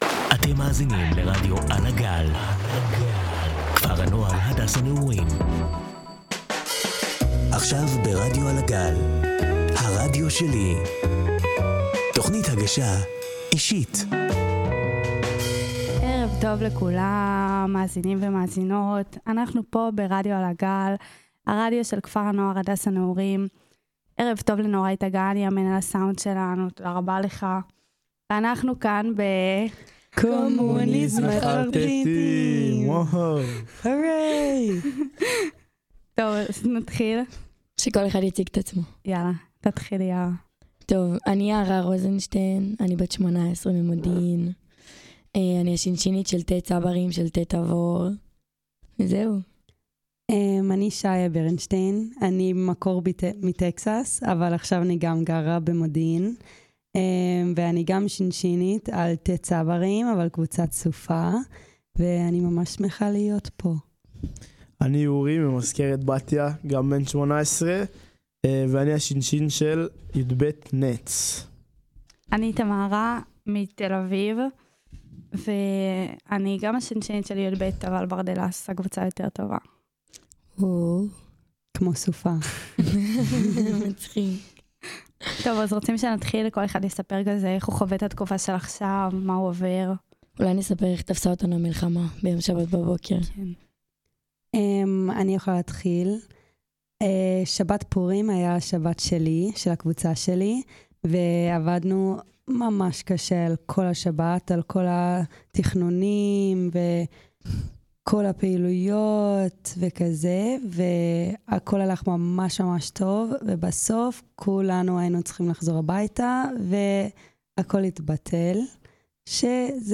הקומונה באולפן